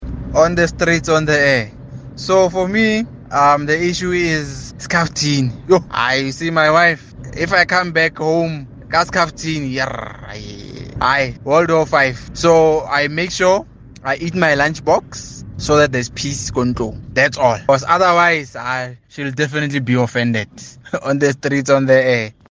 Listen to what Kaya Drive listeners had to say: